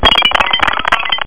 BrokenGlassMelody.mp3